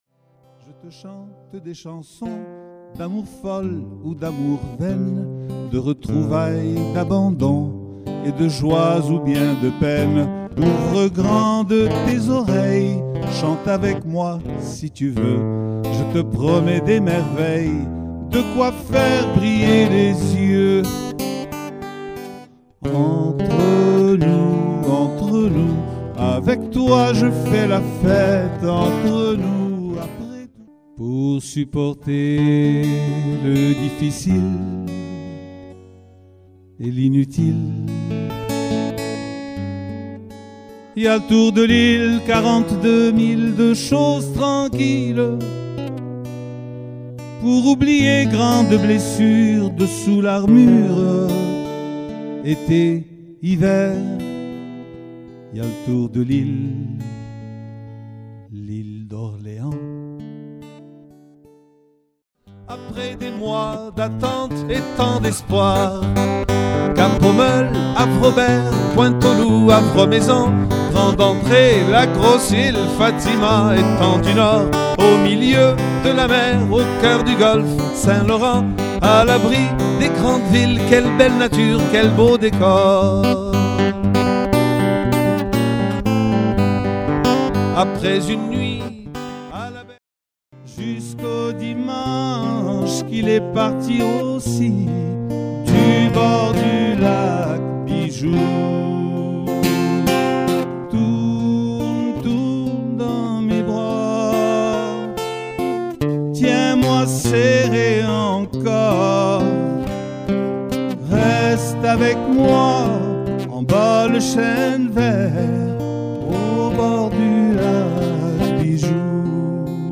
auteur-compositeur-interprète qui chante et s'accompagne à la guitare